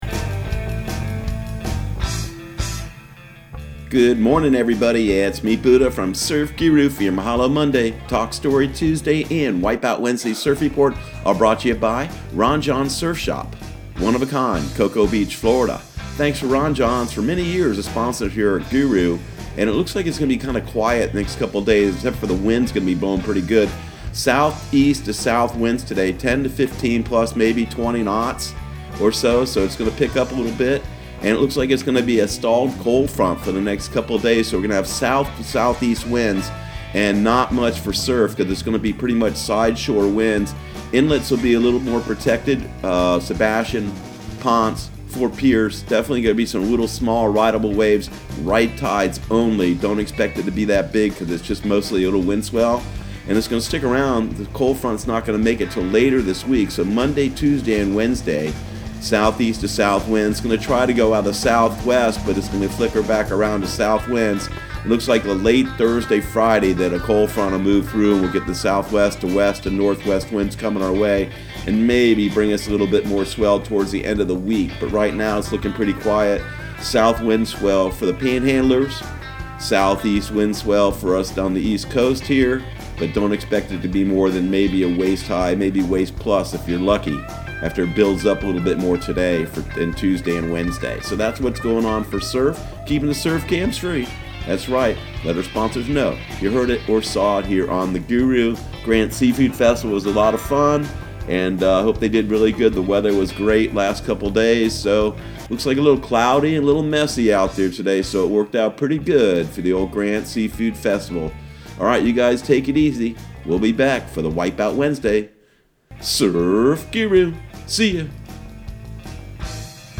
Surf Guru Surf Report and Forecast 03/02/2020 Audio surf report and surf forecast on March 02 for Central Florida and the Southeast.